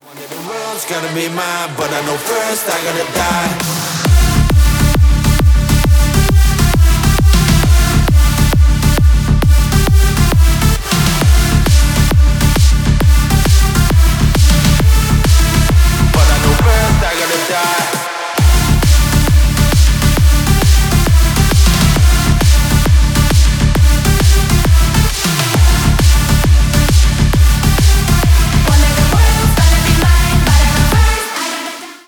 Поп Музыка # Танцевальные
клубные